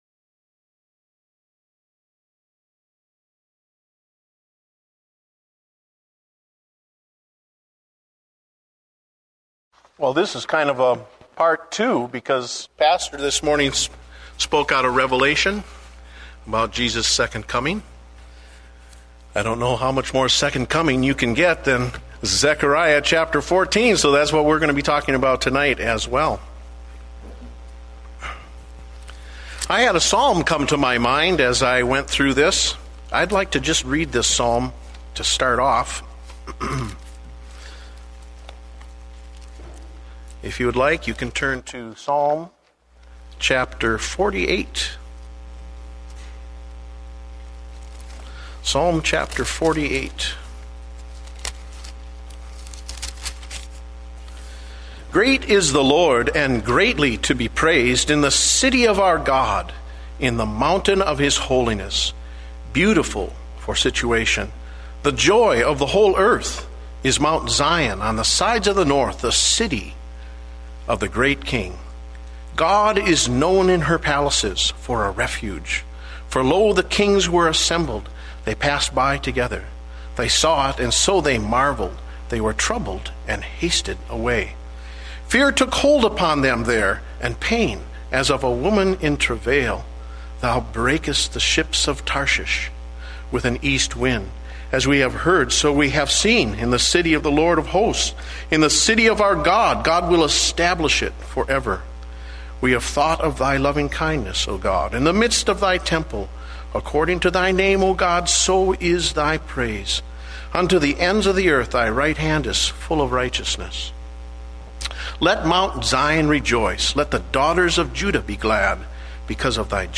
Date: January 4, 2009 (Evening Service)